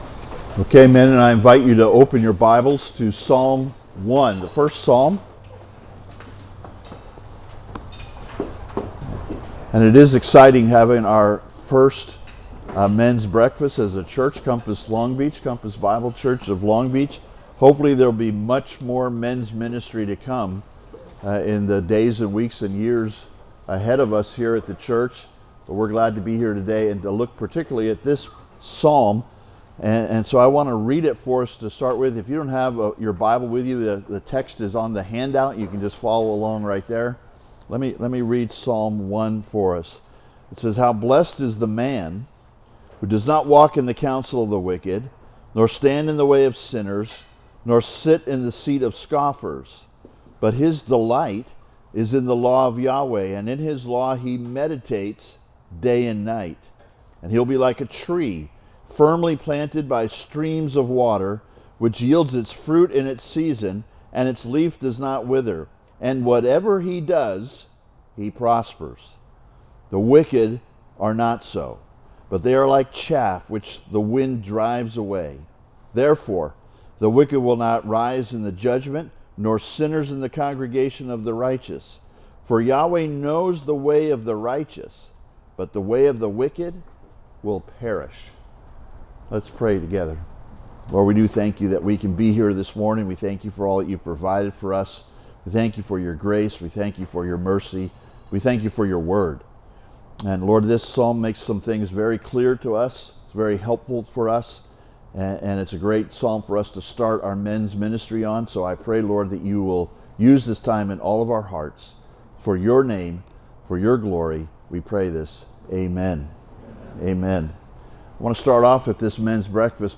Men’s Breakfast - The Blessed Man (Sermon) - Compass Bible Church Long Beach